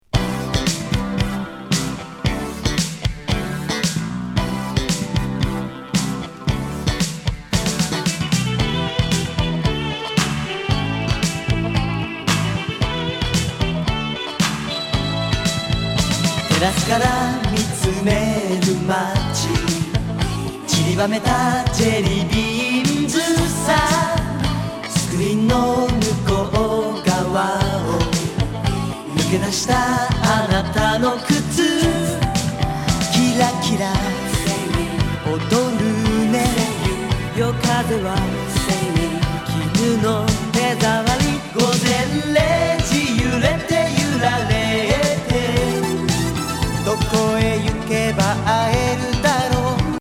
バレアリック・モダン・ソウル